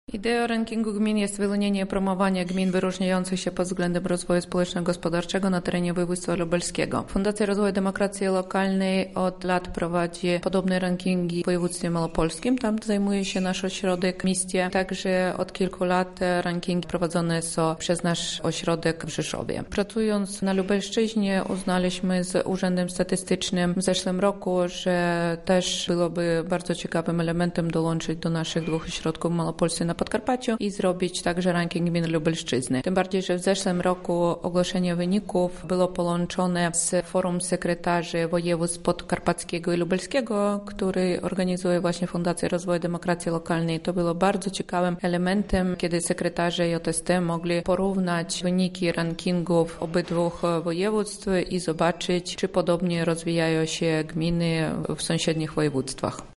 O genezie całego konkursu rozmawialiśmy